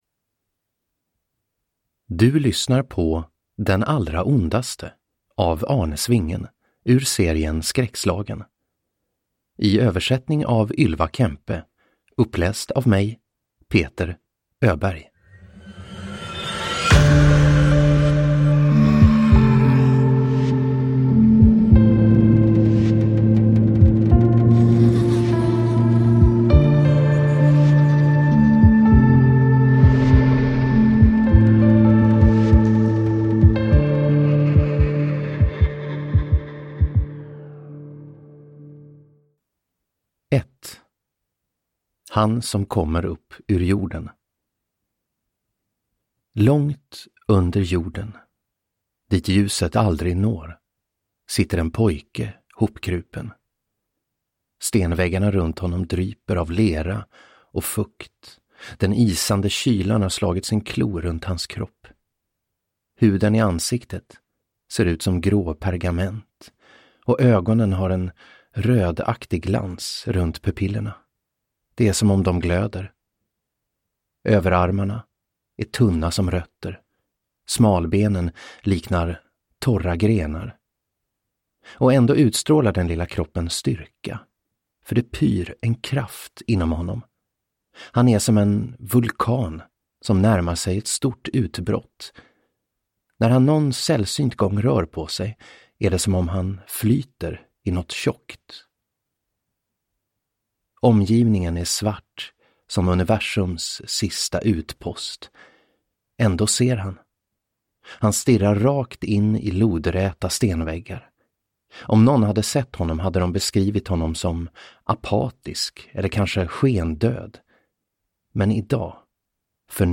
Den allra ondaste – Ljudbok